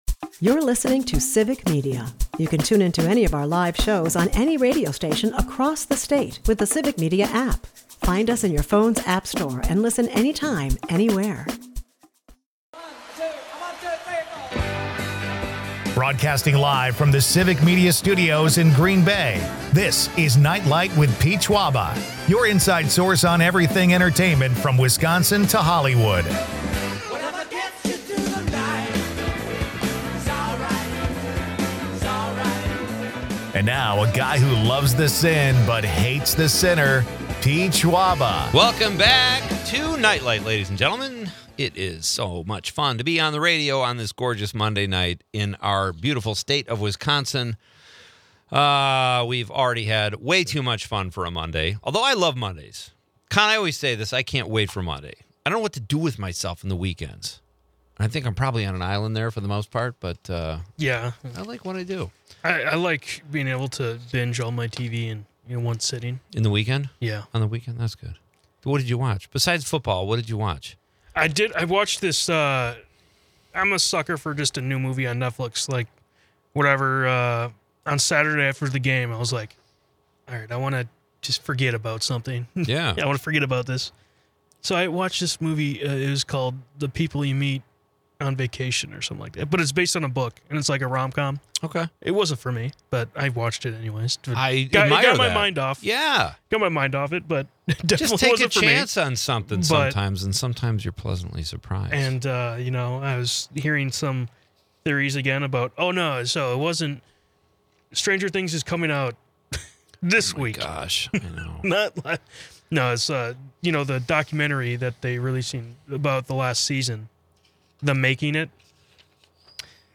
They reminisce about the comedy past and current industry challenges. The show wraps with audience calls about food traditions, ranging from Thanksgiving feasts to tailgating.